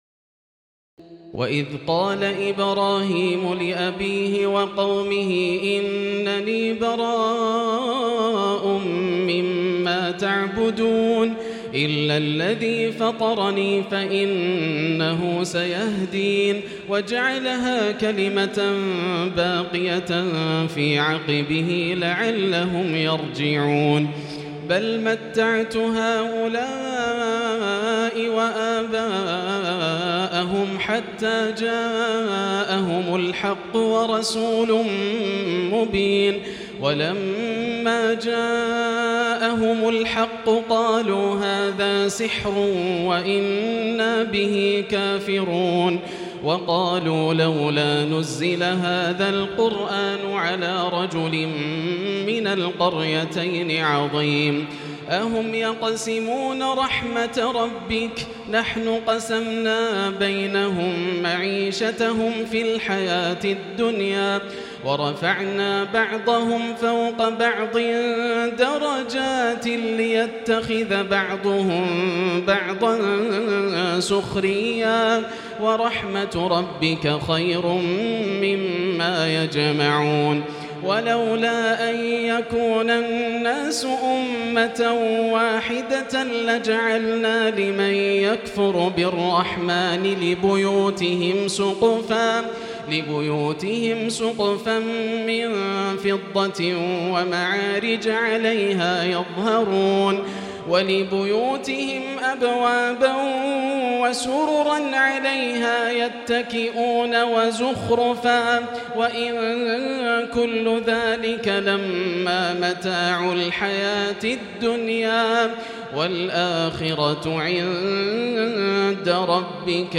تراويح ليلة 24 رمضان 1438هـ من سور الزخرف (26-89) والدخان و الجاثية Taraweeh 24 st night Ramadan 1438H from Surah Az-Zukhruf and Ad-Dukhaan and Al-Jaathiya > تراويح الحرم المكي عام 1438 🕋 > التراويح - تلاوات الحرمين